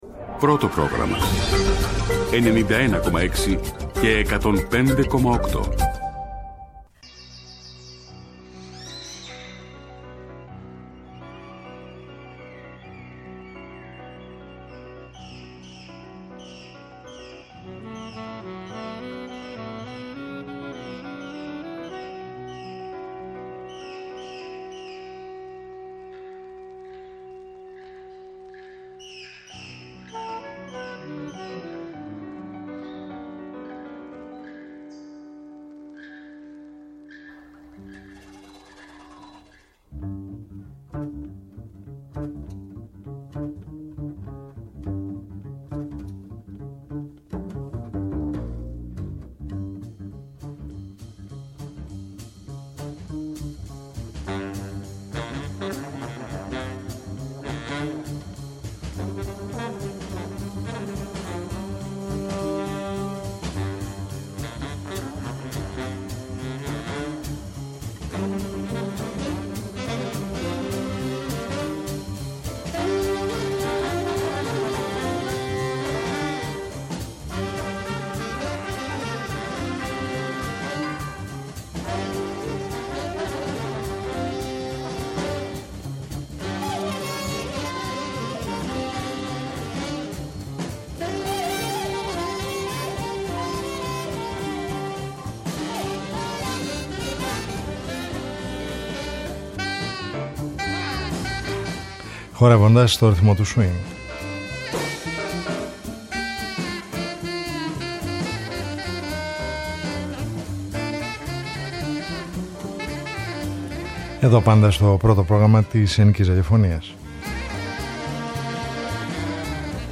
Χορεύοντας στον ρυθμό του Swing : Μια εβδομαδιαία ωριαία συνάντηση με τις διάφορες μορφές της διεθνούς και της ελληνικής jazz σκηνής, κάθε Σάββατο στις 23:00 στο πρώτο Πρόγραμμα.